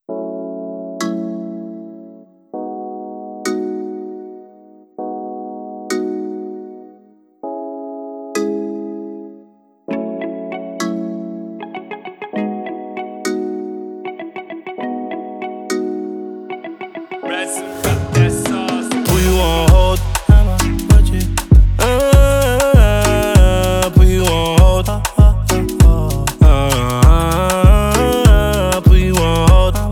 • Afrobeats